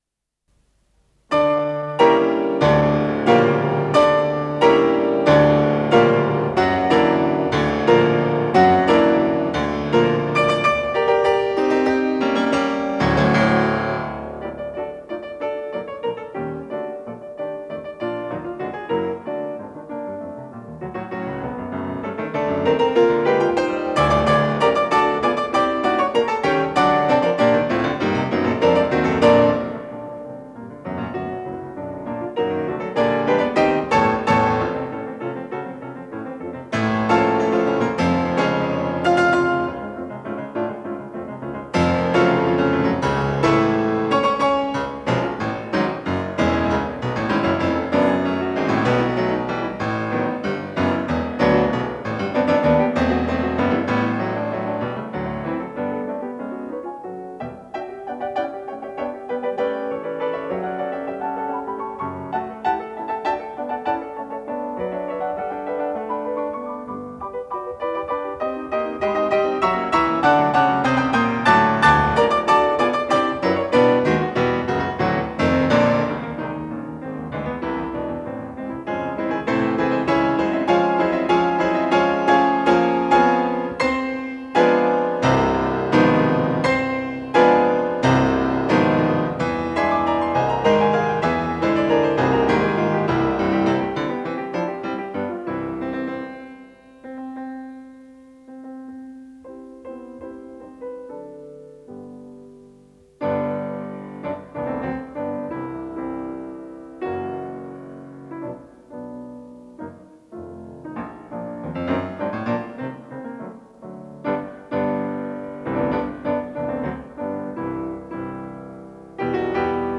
с профессиональной магнитной ленты
фортепиано